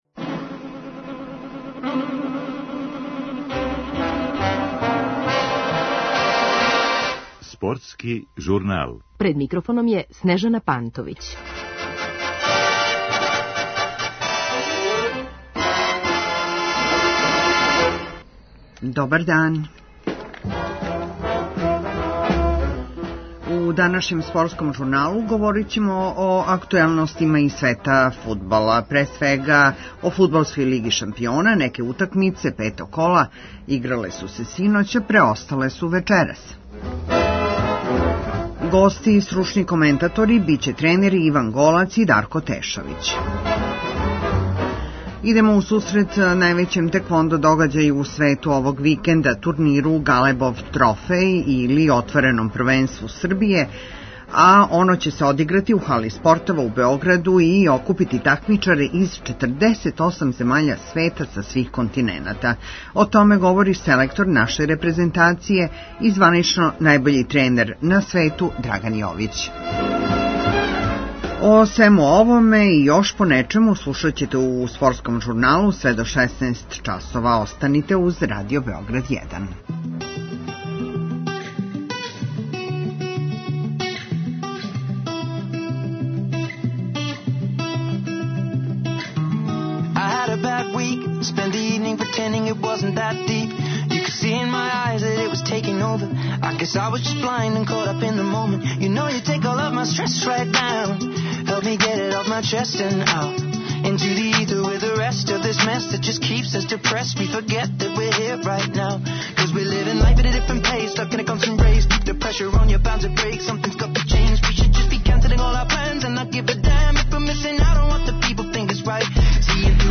Гости стручни коментатори - фудбалски тренери